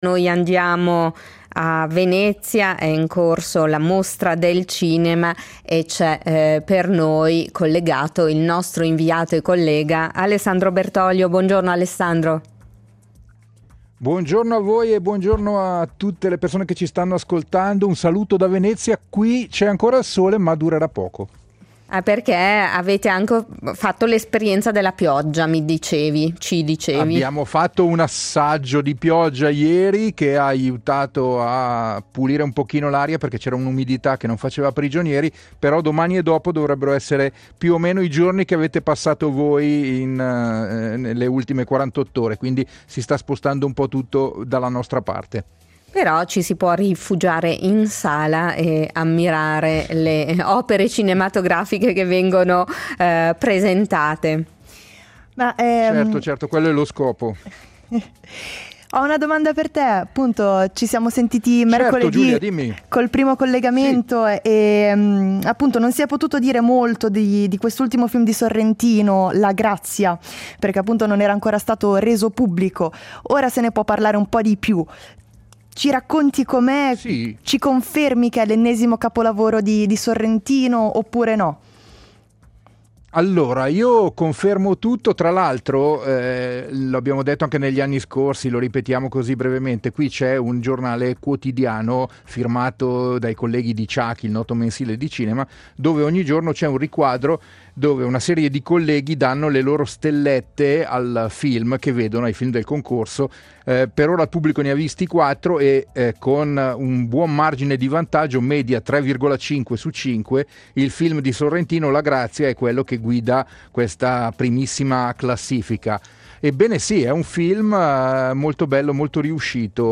In diretta da Venezia